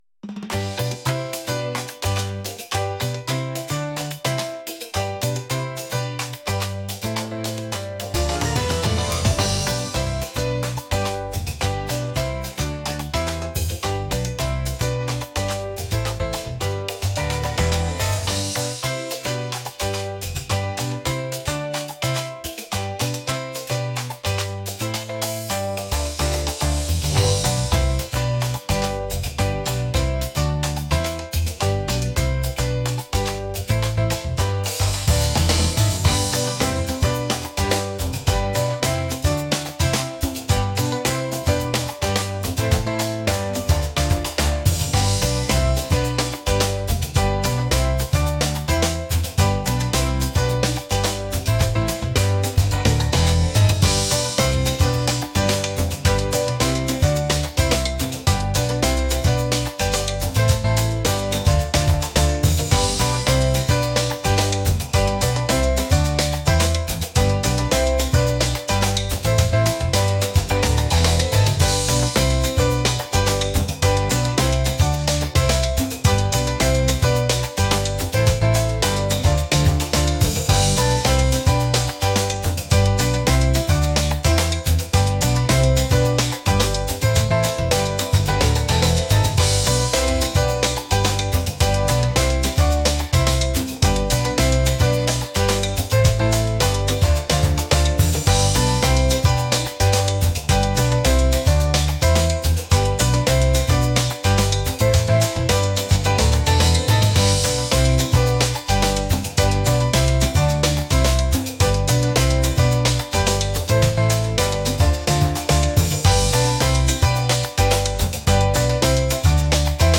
upbeat | latin